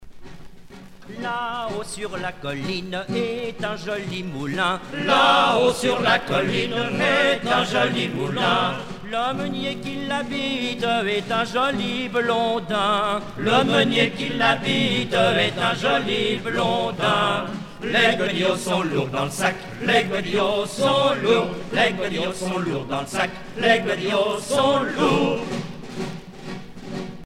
Genre laisse
Pièce musicale éditée